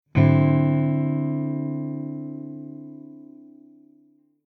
Here are some samples of a D9 Chord with the root at the D note on the 5th fret.
D9 Chord Sound Sample 1
D9th Funk Chord